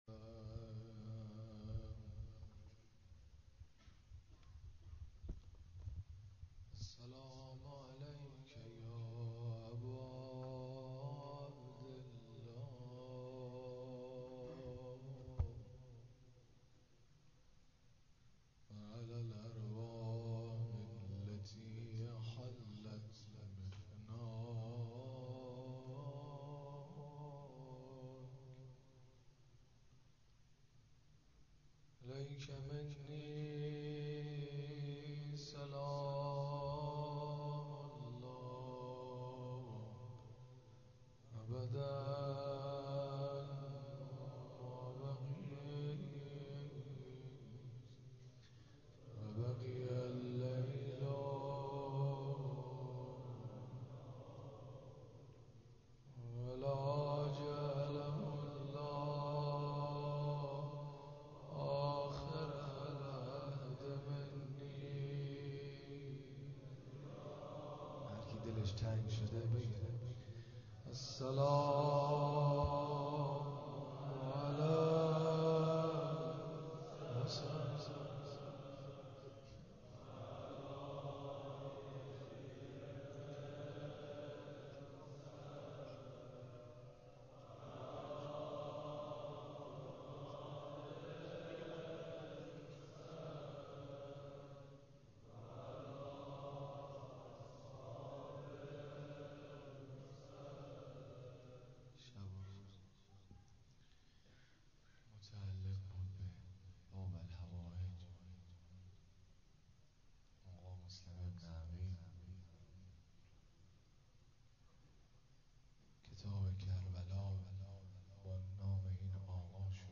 حسینیه حضرت زینب (سلام الله علیها)